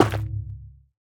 Minecraft Version Minecraft Version 1.21.5 Latest Release | Latest Snapshot 1.21.5 / assets / minecraft / sounds / block / shroomlight / break4.ogg Compare With Compare With Latest Release | Latest Snapshot
break4.ogg